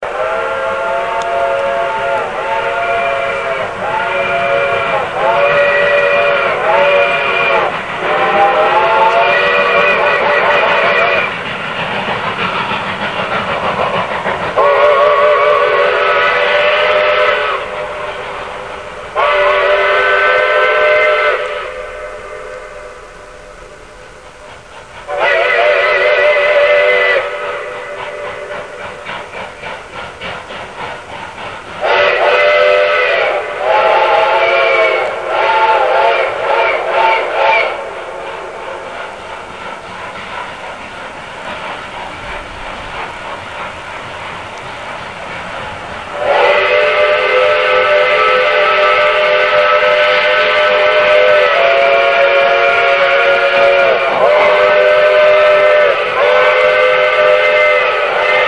steam loco